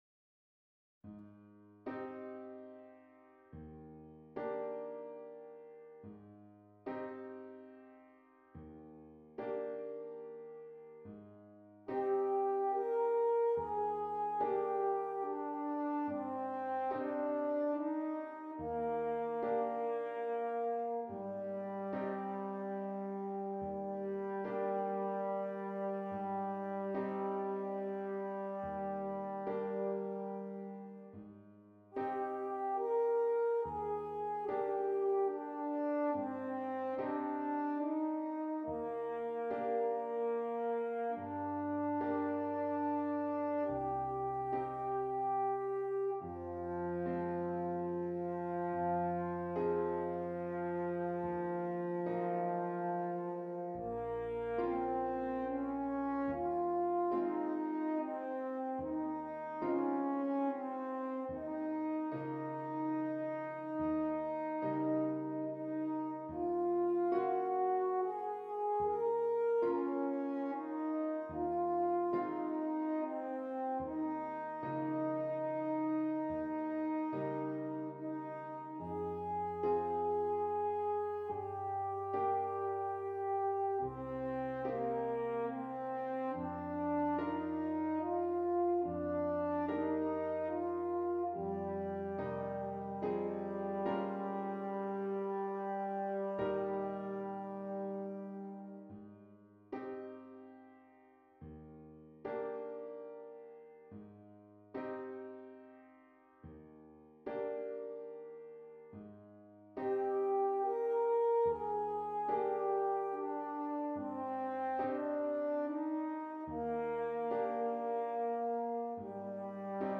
F Horn and Keyboard